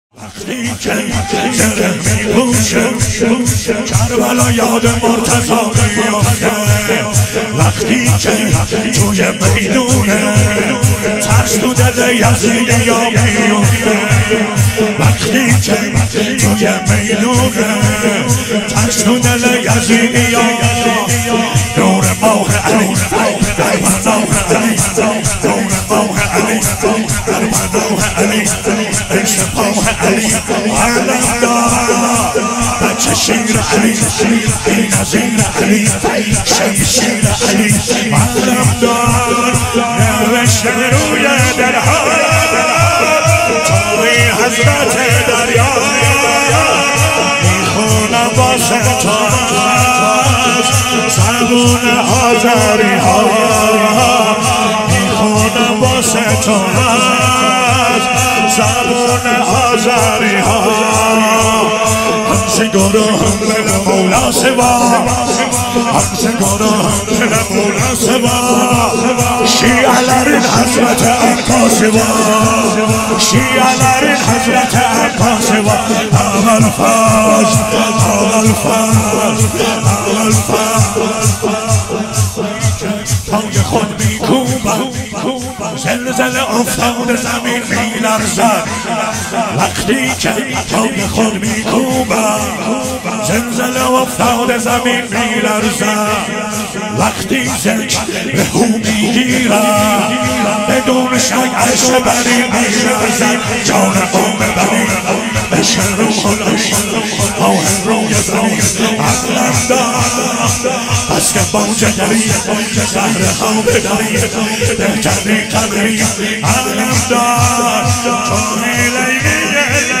مداحی
هیئت جوانان سید الشهدا (ع) تهران